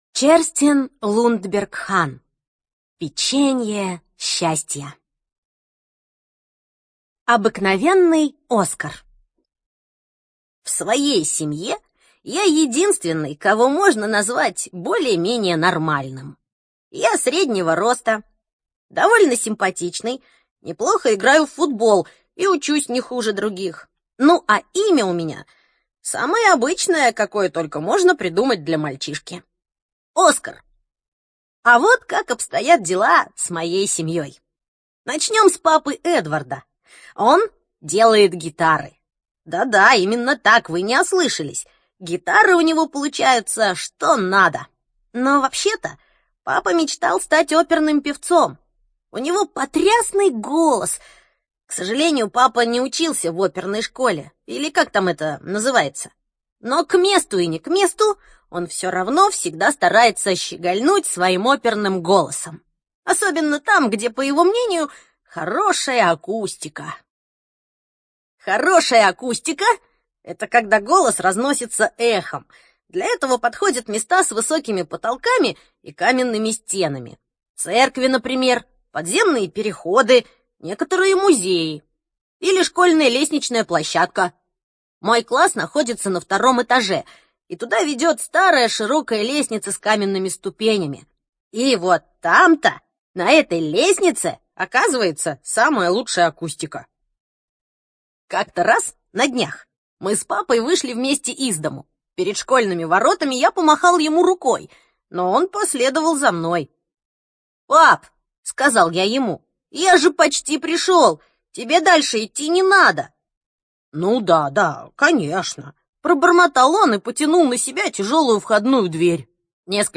ЖанрДетская литература